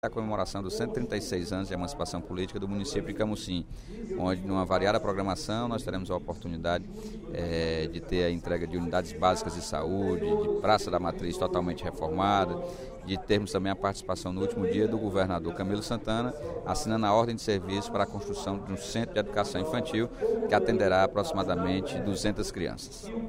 O deputado Sérgio Aguiar (Pros) convidou, no primeiro expediente da sessão plenária desta sexta-feira (25/09), moradores e visitantes de Camocim para os festejos de 136 anos de emancipação do município.